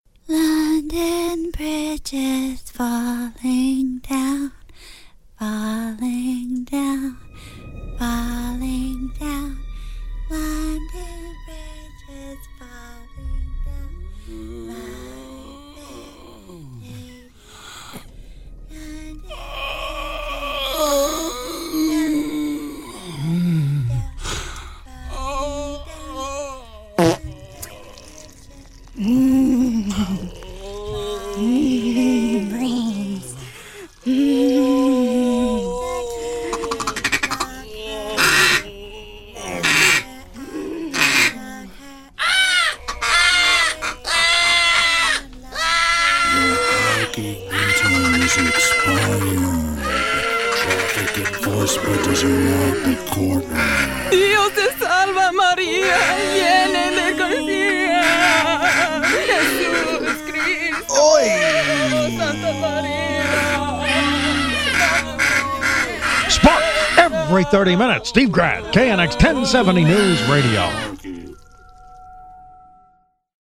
Scary Scary Sound Effect